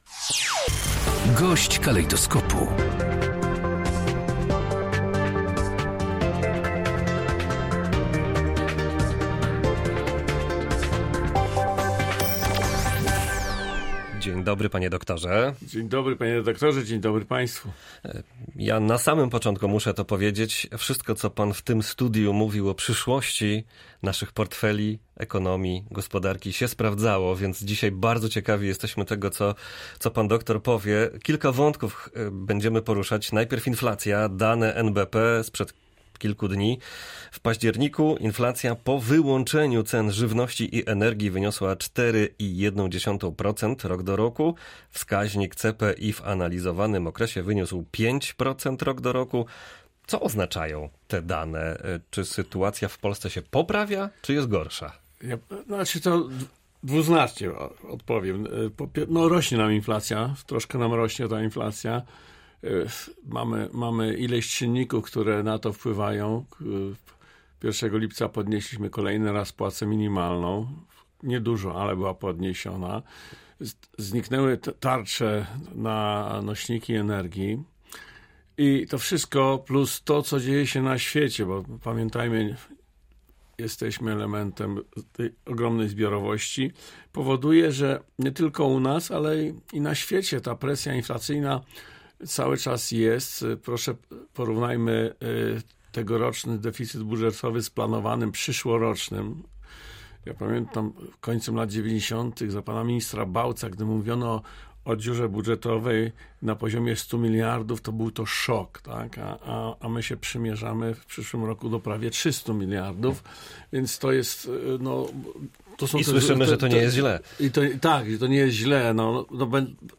GOŚĆ DNIA. Zamrożenie cen prądu da ulgę konsumentom